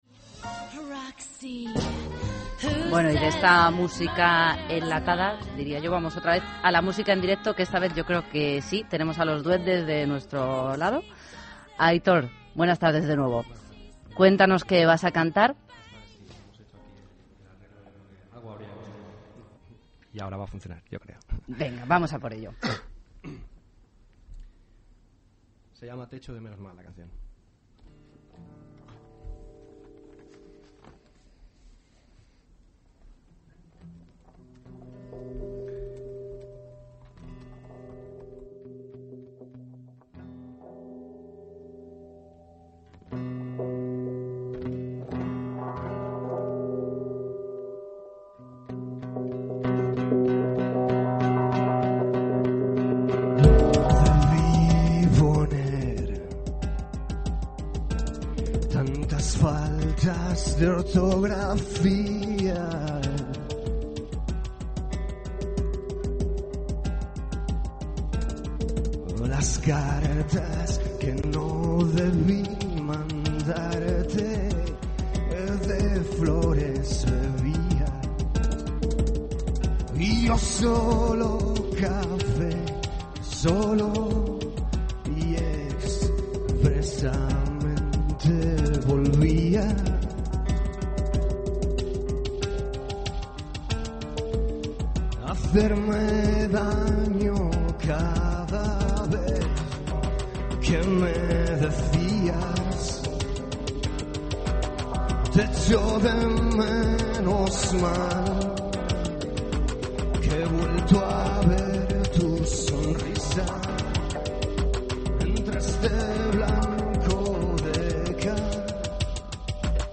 Directo en cadena SER